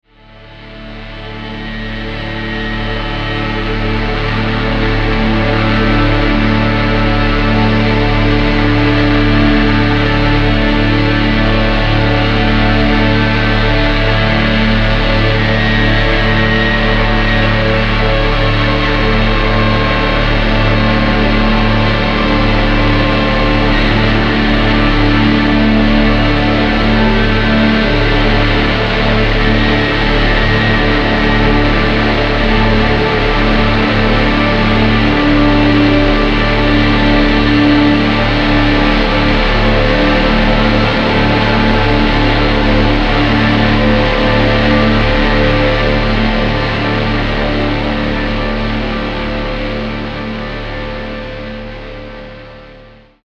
Vocals, Doepfer Modular Synthesizer